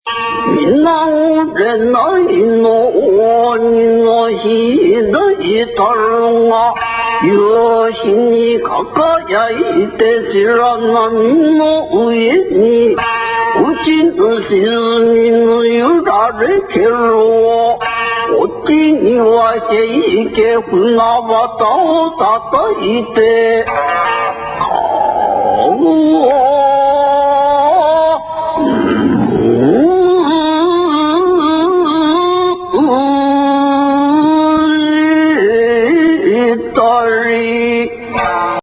Omdat muzikale begeleiding in het gesproken woord een grote rol speelde (hier stukjes uit een
Heike-voordracht en uit een
heikyoku.mp3